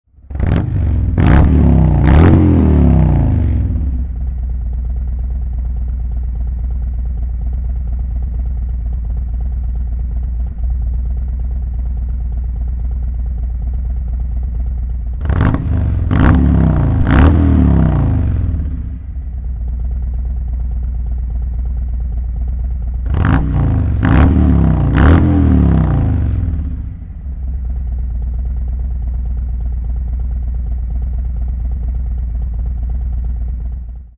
Silencieux Slip-On Mk 45
Utilisant à la fois des garnitures en acier inoxydable et en fibre de verre, ces silencieux créent un son de performance profond et une puissance améliorée.